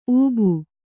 ♪ ūbu